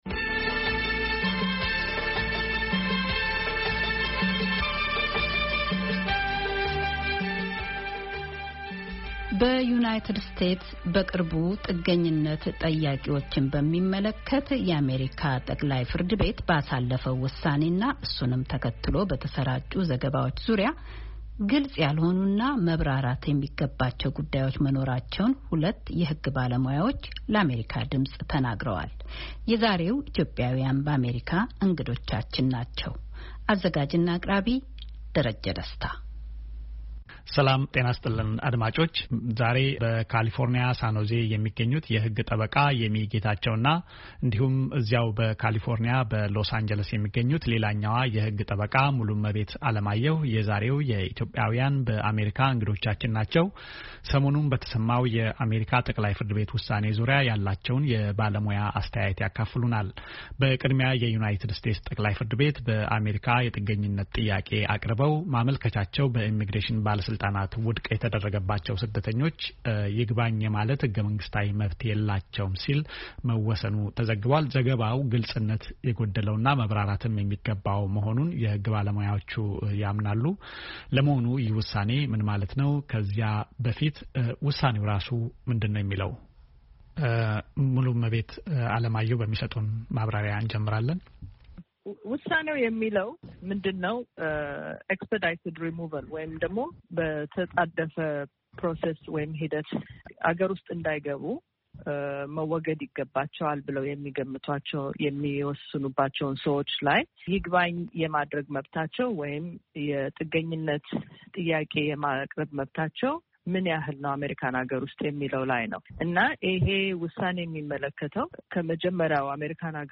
በዩናይትድ ስቴትስ በቅርቡ ጥገኝነት ጠያቂዎችን በሚመለከት የአሜሪካ ጠቅላይ ፍርድ ቤት ባሳለፈው ውሳኔና እሱንም ተከትሎ በተሰራጩ ዘገባዎች ዙሪያ ግልጽ ያልሆኑና መብራራት የሚገባቸው ጉዳዮች መኖራቸውን ሁለት የህግ ባለሙያዎች ለአሜሪካ ድምጽ ተናግረዋል፡፡